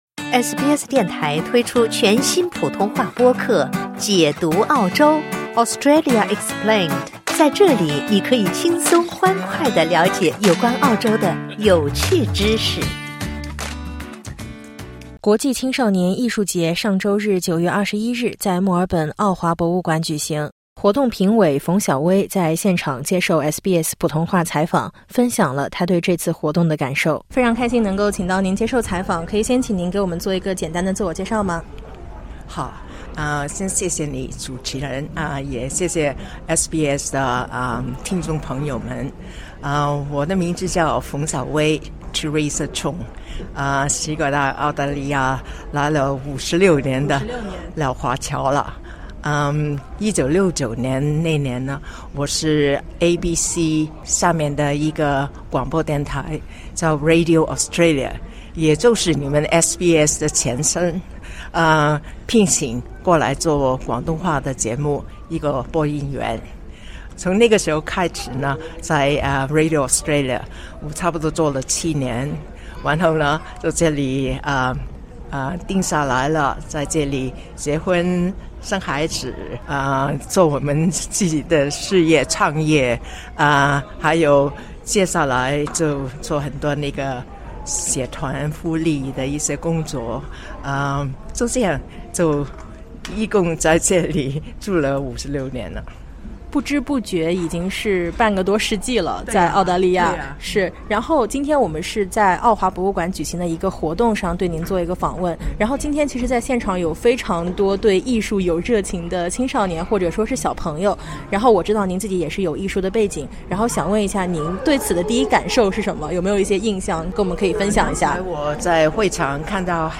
同样接受采访的还有维州议员希娜•瓦特，她在现场分享了不久前公布的“维多利亚州中国战略”，以及其中对华人社群的支持。点击音频，收听完整报道。